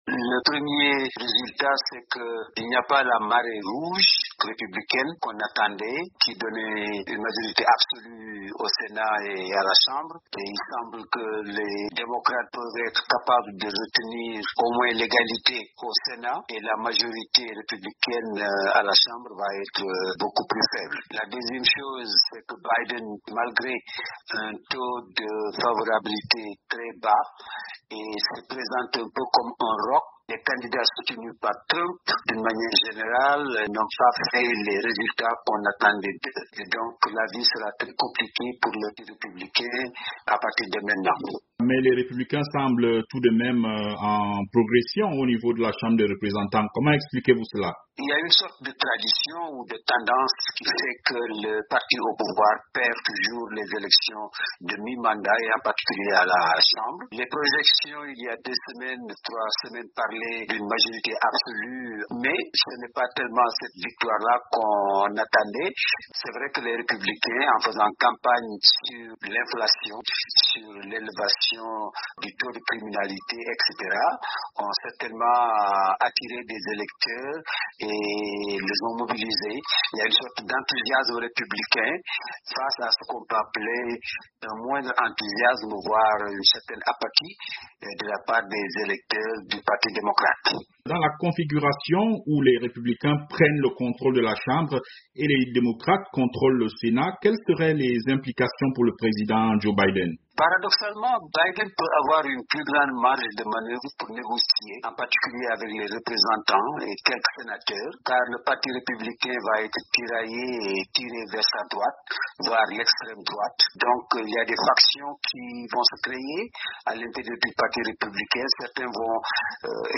analyste politique